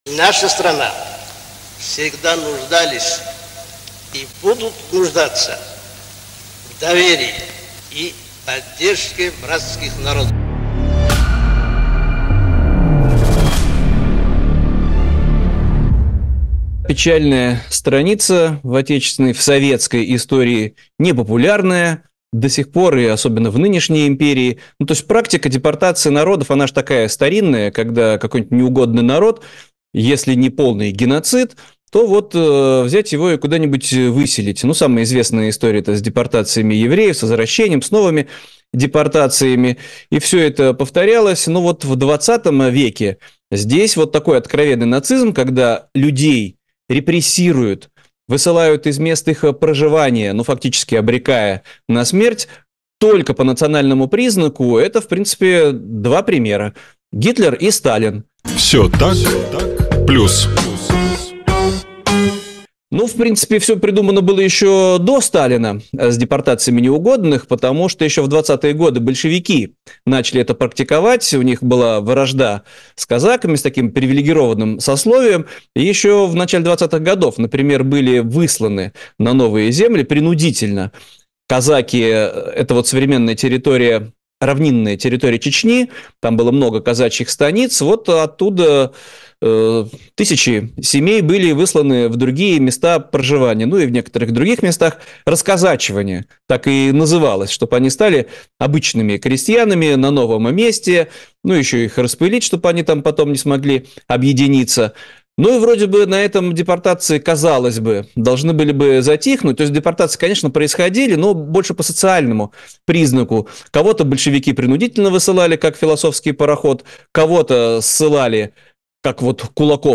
журналист
историк